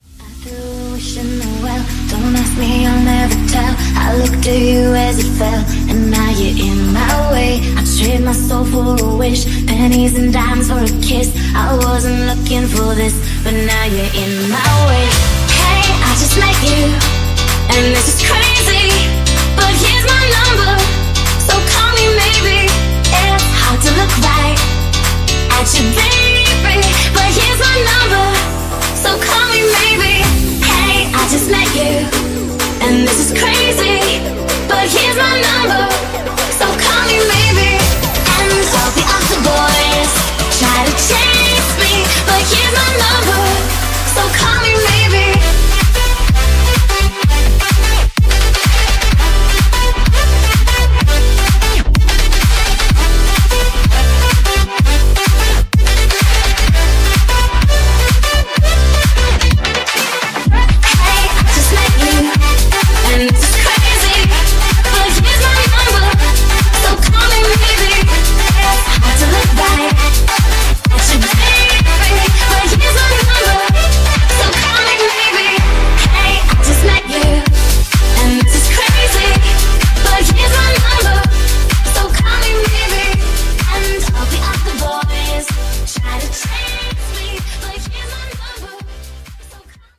Genre: DRUM AND BASS
Clean BPM: 170 Time